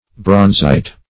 Search Result for " bronzite" : The Collaborative International Dictionary of English v.0.48: Bronzite \Bronz"ite\, n. [Cf. F. bronzite.]